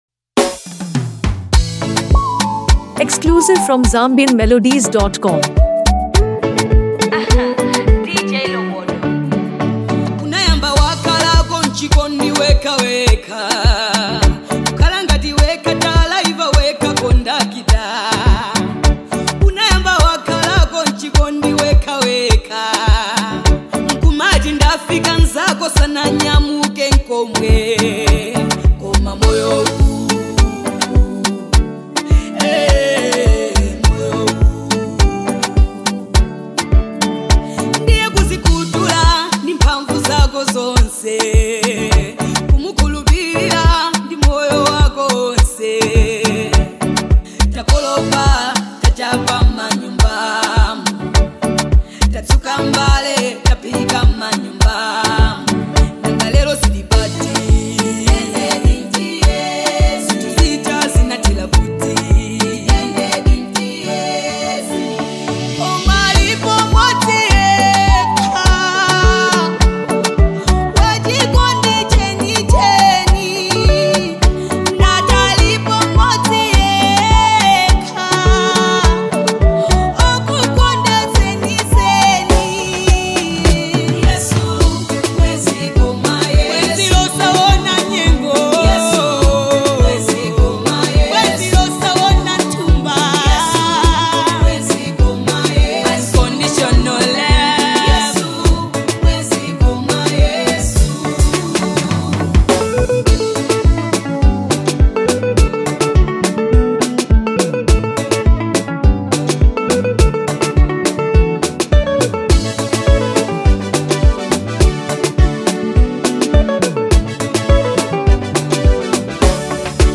and culture into a powerful gospel anthem.
With touching vocals and inspiring lyrics